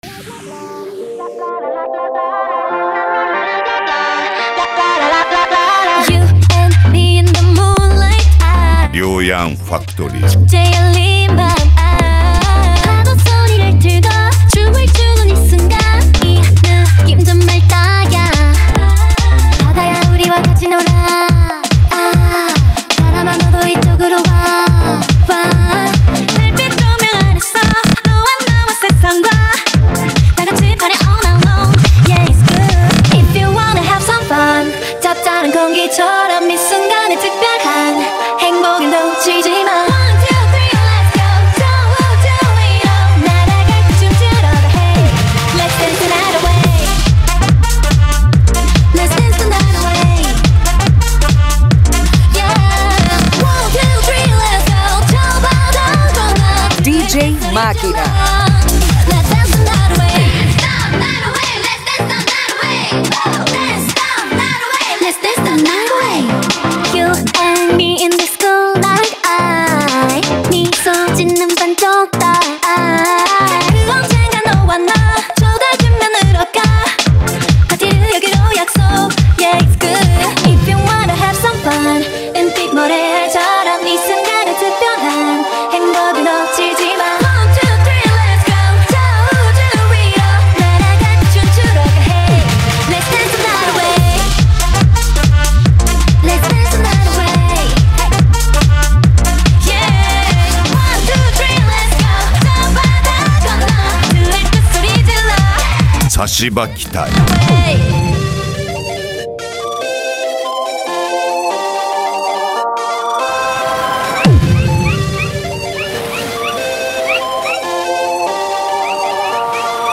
Eletronica
japan music
PANCADÃO